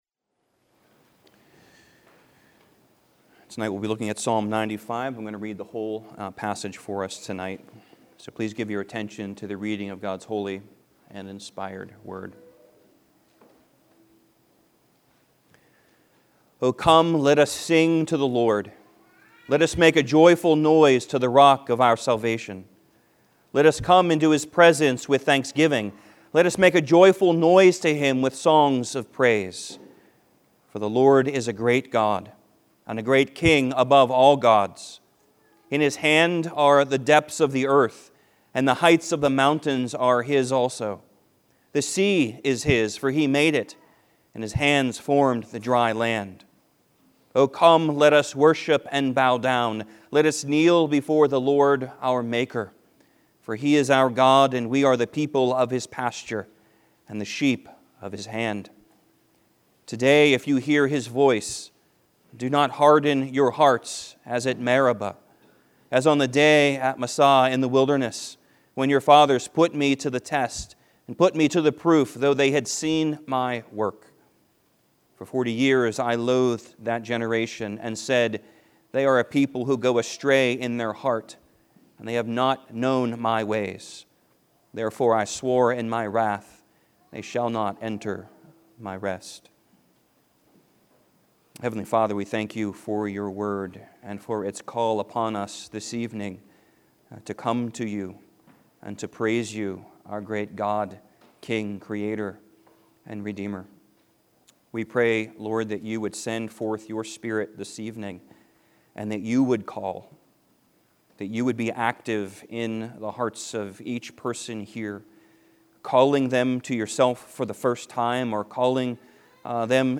Joint evening service with area RP congregations!